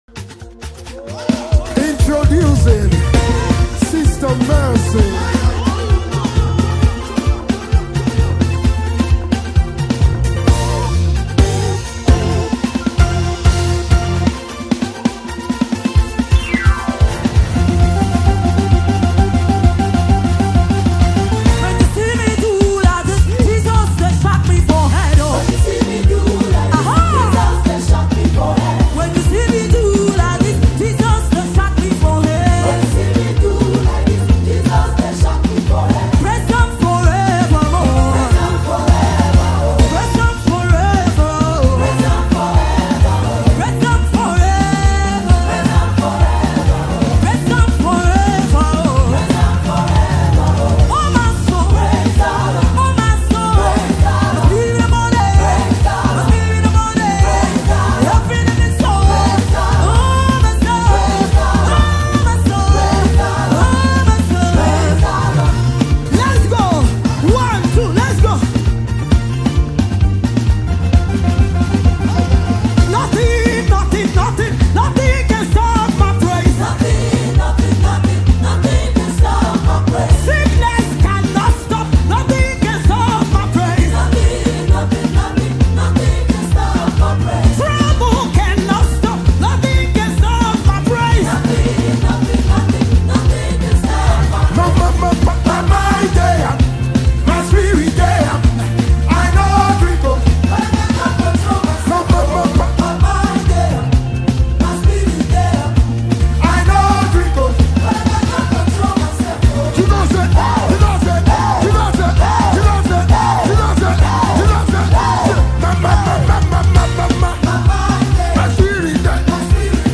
Mp3 Gospel Songs
a dynamic and spirit-filled gospel music group
the talented singer
soulful voice
harmonious vocals